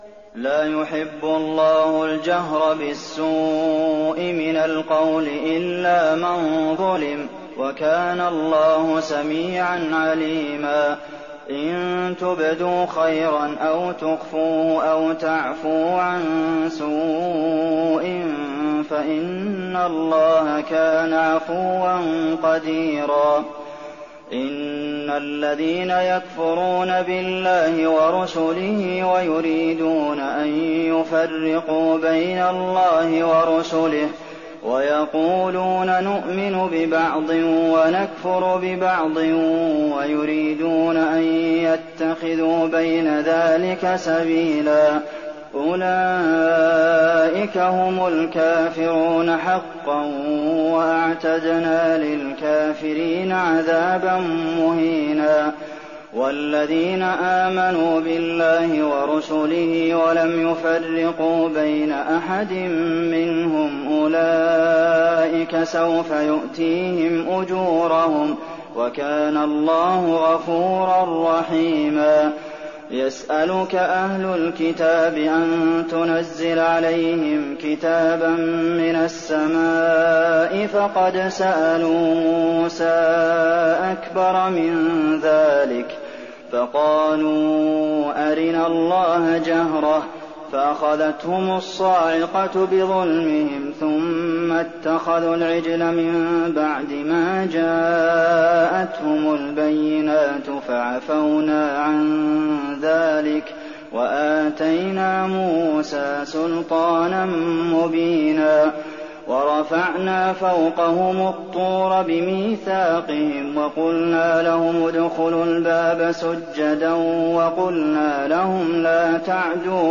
تراويح الليلة السادسة رمضان 1419هـ من سورتي النساء (148-176) و المائدة (1-26) Taraweeh 6th night Ramadan 1419H from Surah An-Nisaa and AlMa'idah > تراويح الحرم النبوي عام 1419 🕌 > التراويح - تلاوات الحرمين